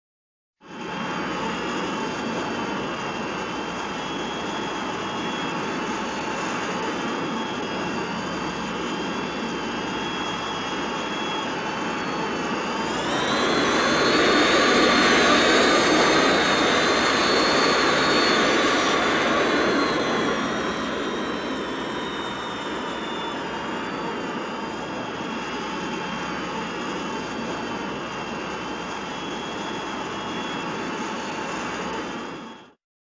BSG FX - Viper - In flight, accelerate 01
BSG_FX_-_Viper_-_In_flight2C_accelerate_01.wav